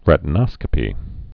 (rĕtn-ŏskə-pē)